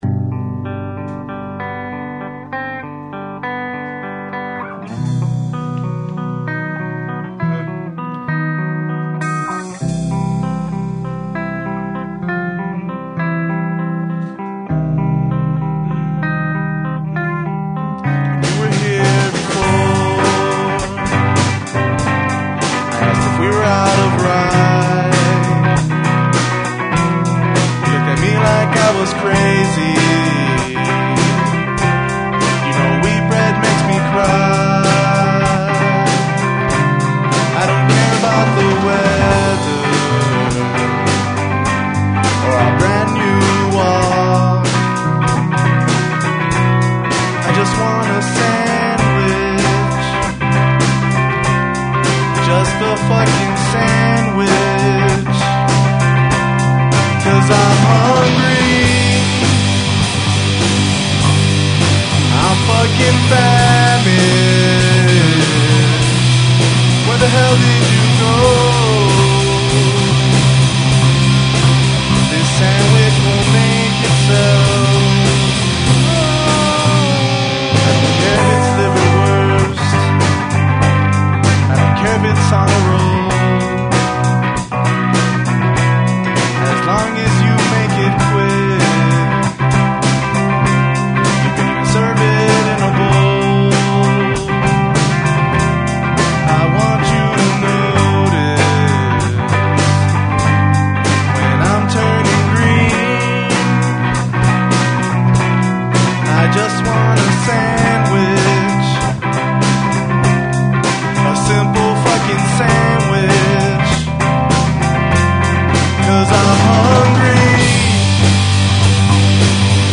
words/vocals